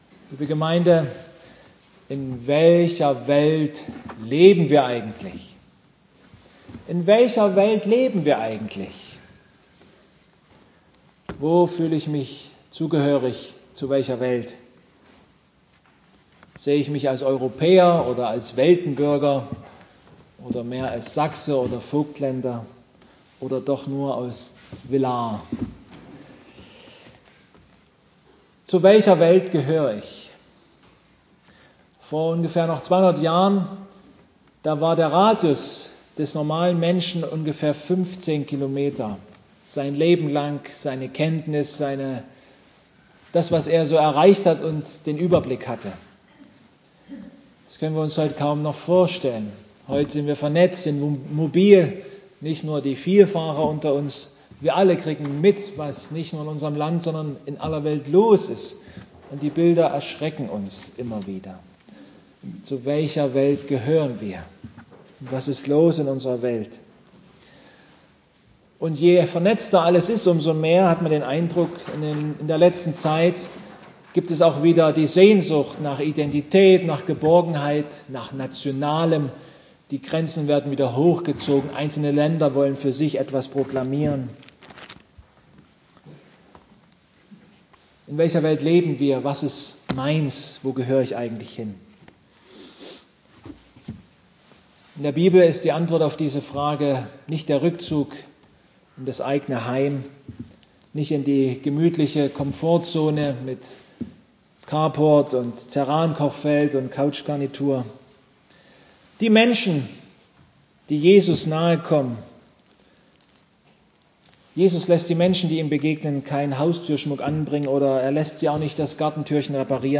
Passage: Johannes 17,4-9 Gottesdienstart: Predigtgottesdienst